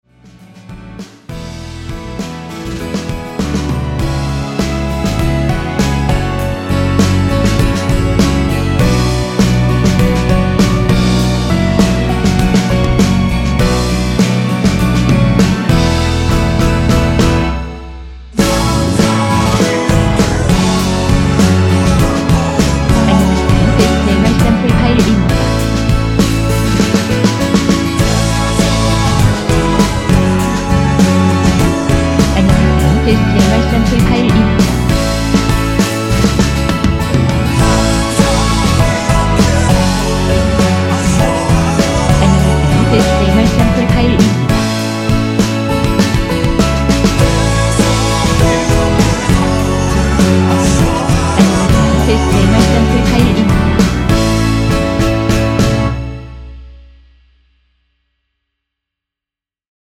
이곡은 원곡자체에 코러스가 마지막 부분만 나오며 미리듣기 부분의 코러스가 전부 입니다.
원키에서(-2)내린 코러스 포함된 MR입니다.
앞부분30초, 뒷부분30초씩 편집해서 올려 드리고 있습니다.
중간에 음이 끈어지고 다시 나오는 이유는